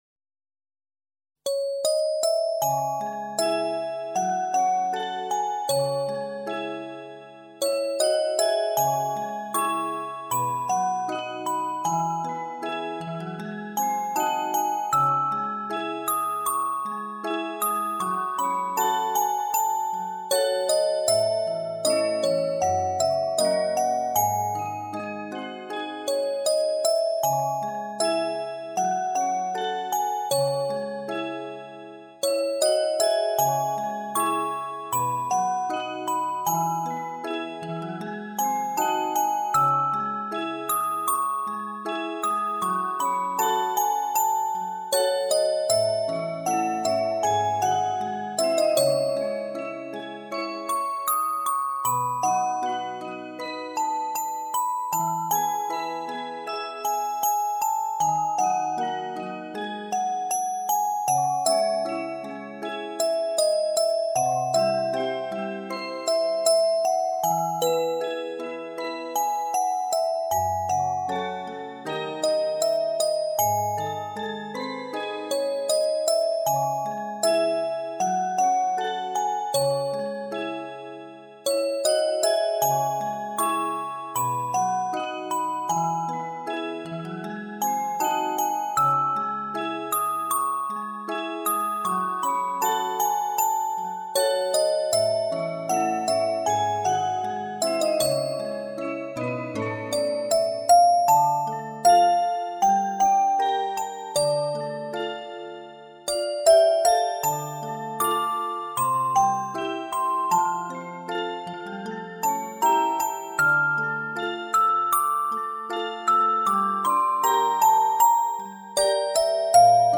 原创]八音盒音乐－印象 这是一首让人安静的音乐。
愿这首清凉的音乐，在这盛夏酷暑中，给您带去一片清凉！ 在这叮叮咚咚的乐声中，拥有一个夏夜好梦，祝激动的每一位朋友清凉、无蚊！